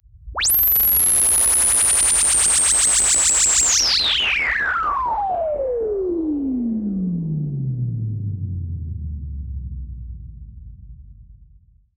Roland E Noise 10.wav